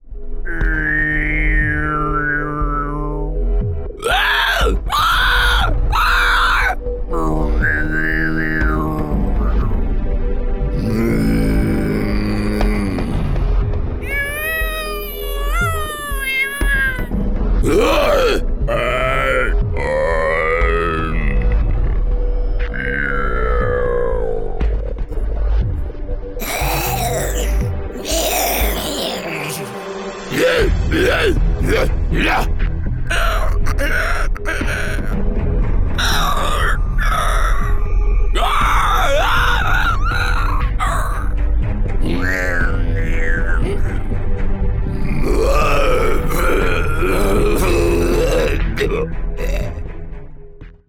Impersonations
– Recorded in a sound-treated booth
I specialise in performing Voice overs in both American and British accents and dialects.